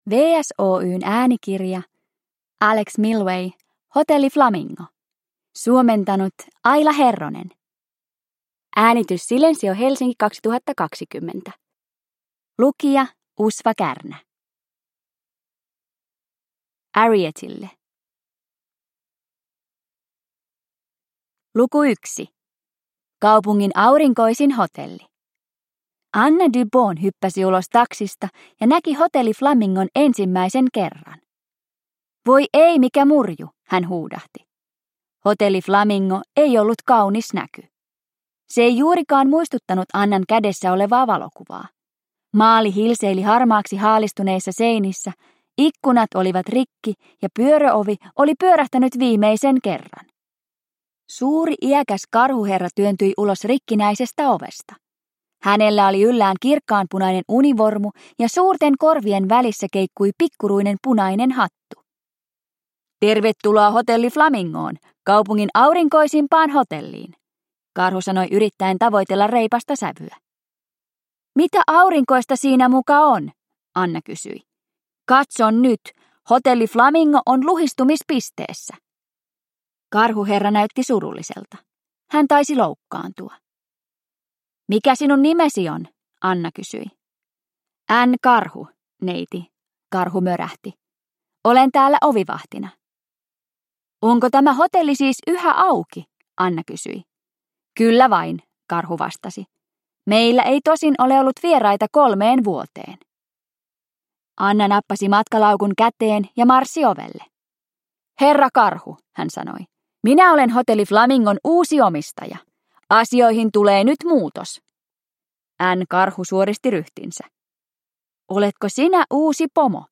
Hotelli Flamingo – Ljudbok – Laddas ner